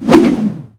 tentakle.ogg